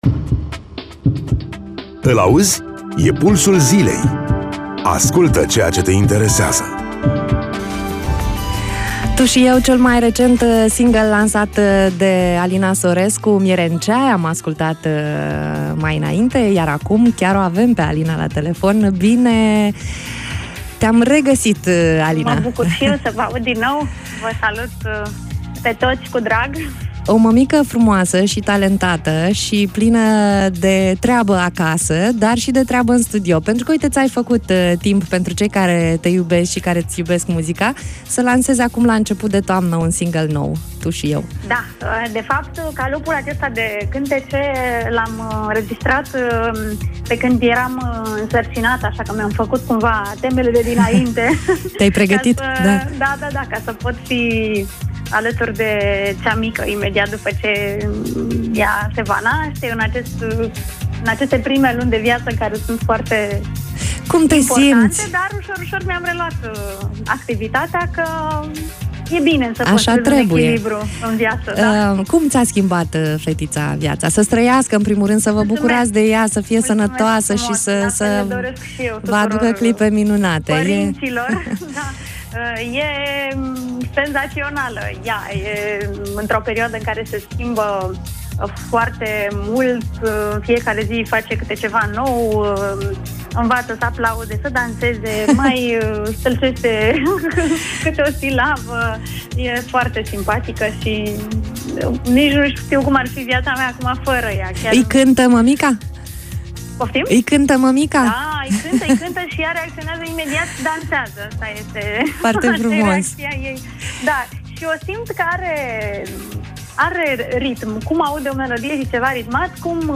10-Nov-Interviu-Alina-Sorescu.mp3